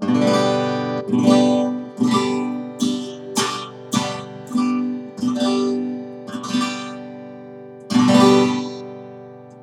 When I record acoustic guitar it sounds like I’ve added a phase shift.
The phase shift does not have a steady rate; it seems to follow the envelope of the recording.
I’m recording an acoustic guitar with a single omnidirectional condenser microphone into a mono channel.
I’d double check the echo cancelling is switched off : as well as being distorted the decay seems very short, consistent with one of those echo cancelling thingies.
The first note of the performance had almost no distortion in it. By about three seconds, the effect was full-on.